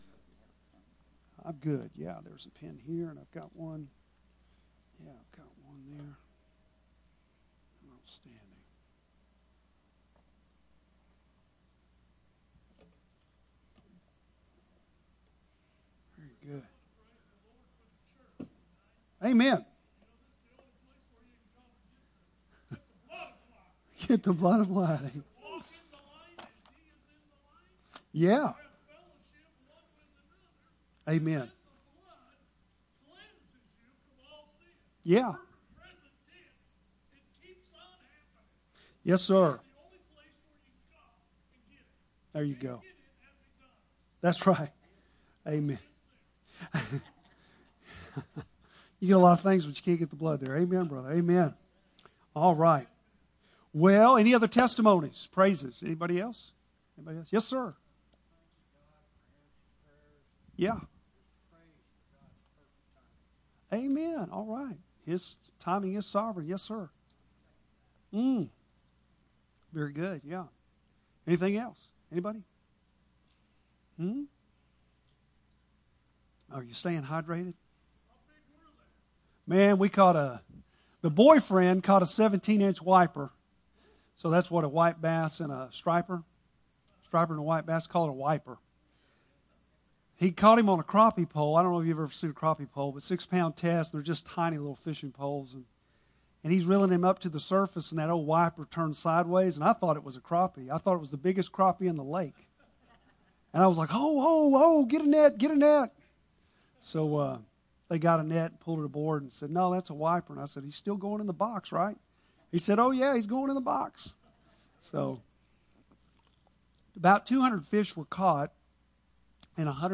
Bible Text: Ruth 1:6-18 | Preacher